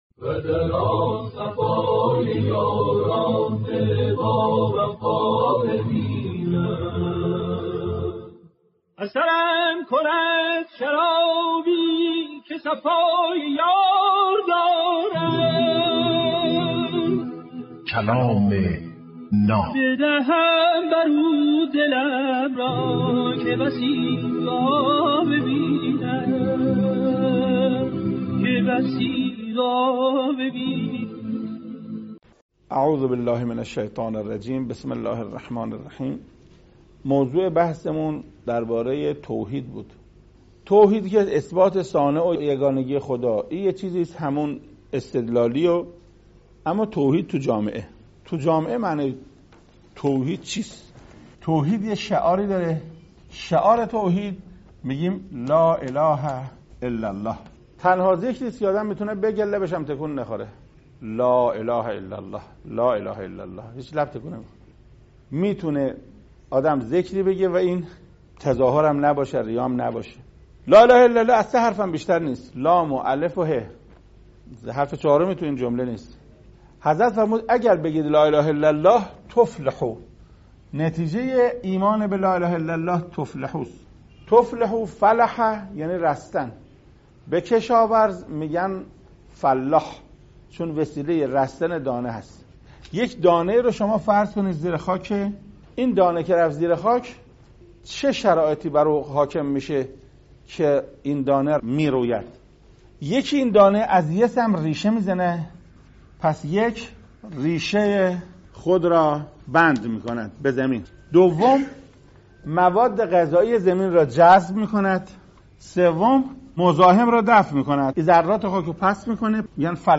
کلام ناب برنامه ای از سخنان بزرگان است که هر روز ساعت 07:08 به وقت افغانستان به مدت 7 دقیقه پخش می شود